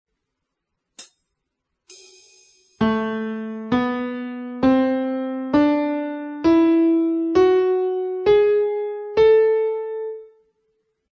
qui la scala di La minore melodica
scala_min_melodica_(64,kb.mp3